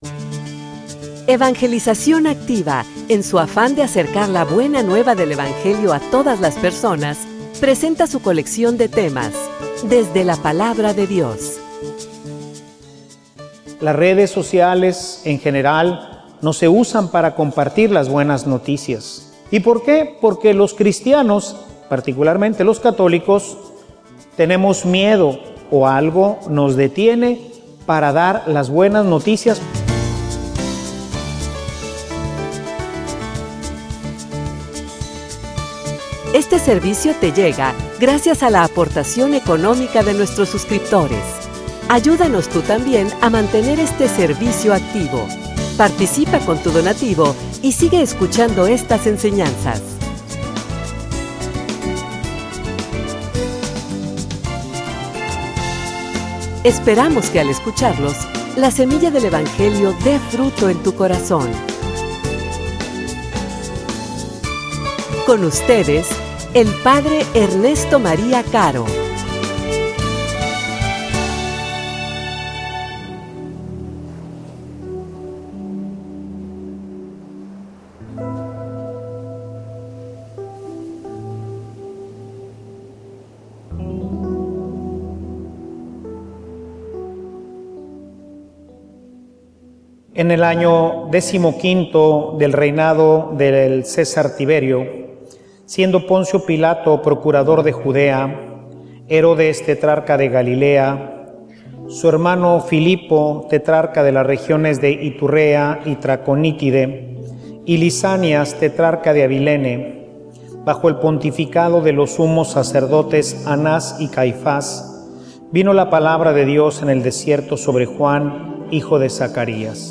homilia_profetas_de_esperanza.mp3